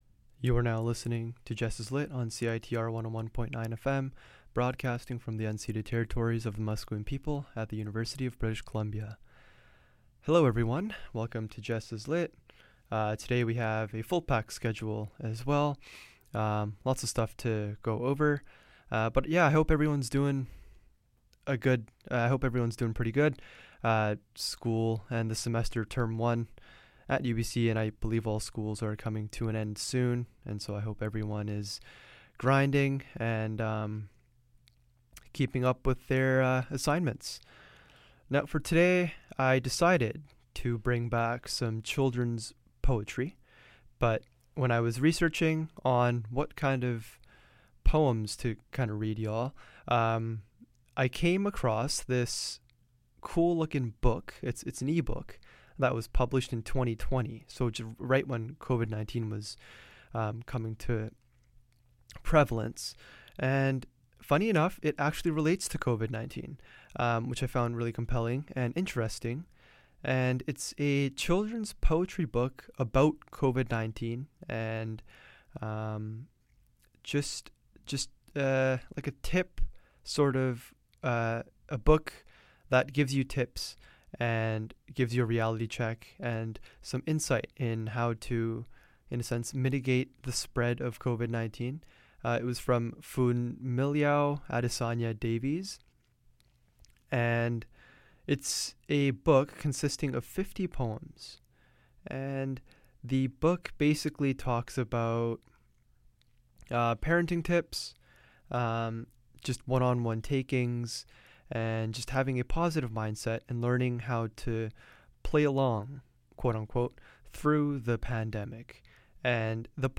In this episode I read a children’s poetry book about Covid-19 from Funmilayo Adesanya-Davies. The book was published in 2020, and was written primarily to give children “tips” to mitigate the spread of COVID-19, and many more. Tune in to immerse yourself with fast, fun rhyming children's poetry!